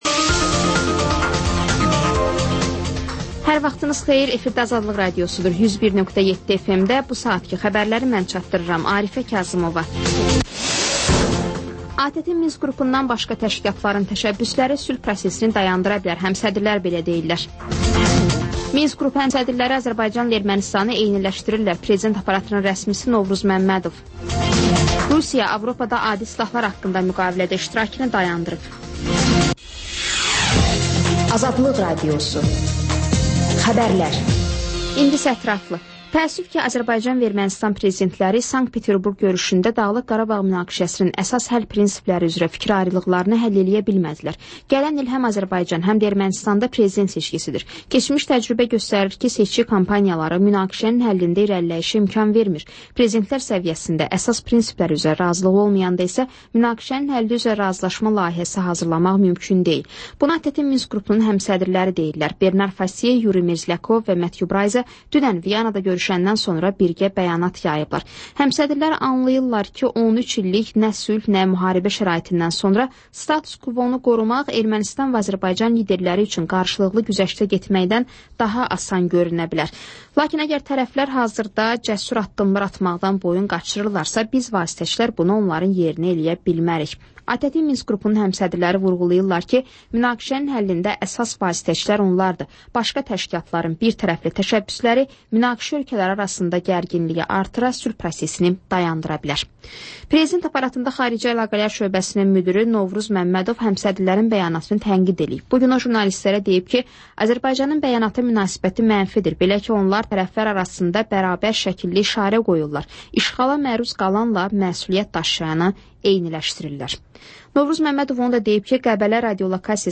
Xəbərlər, ardınca PANORAMA rubrikası: Həftənin aktual mövzusunun müzakirəsi